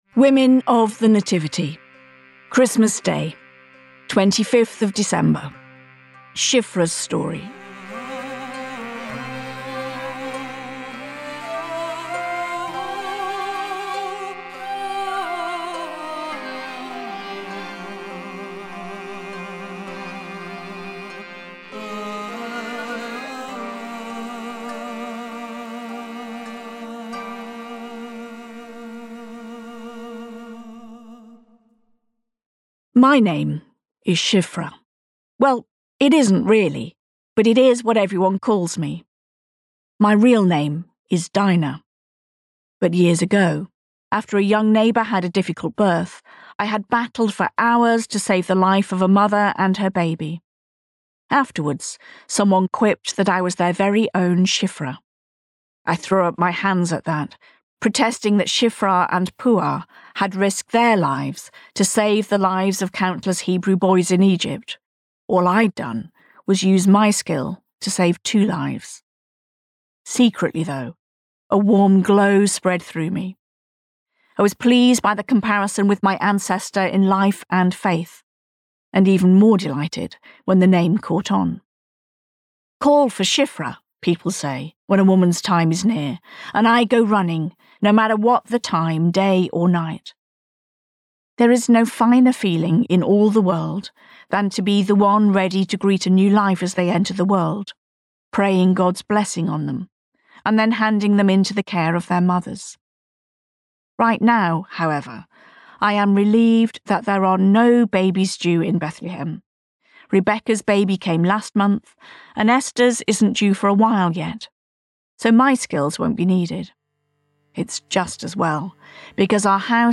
Incidental music